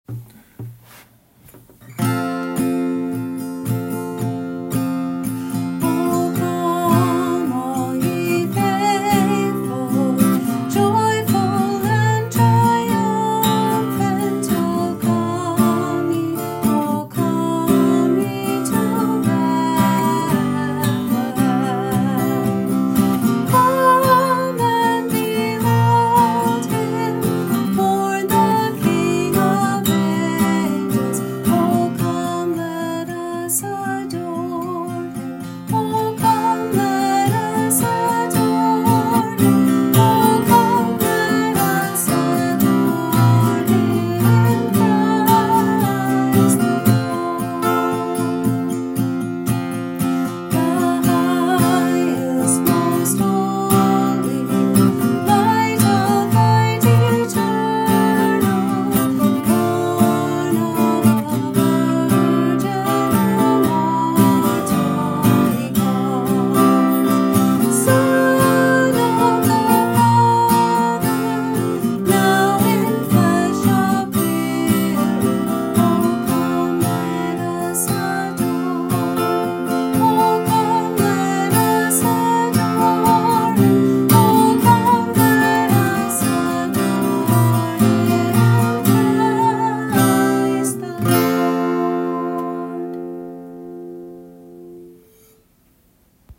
Here is a recording of the exact version we will be singing as a congregation during the program.